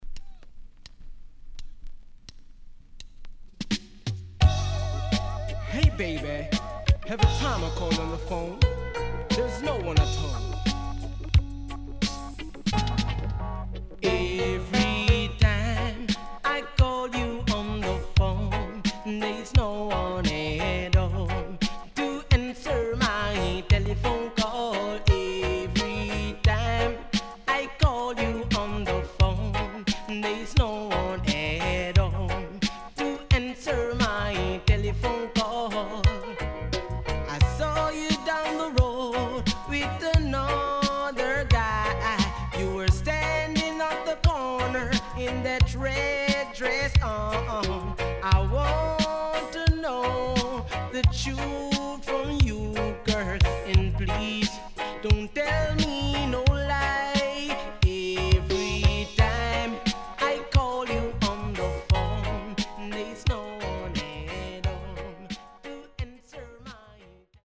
HOME > LP [DANCEHALL]
SIDE B:少しノイズ入りますが良好です。